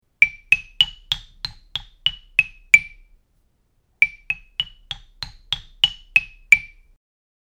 Xilofono 5 toni
Suono piacevole!
• Materiale: legno